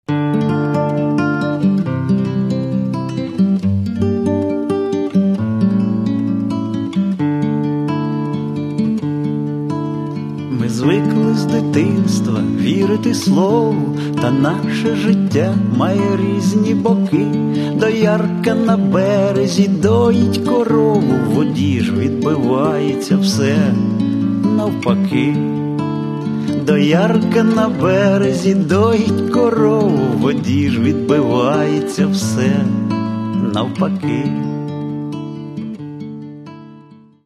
Каталог -> Інше -> Барди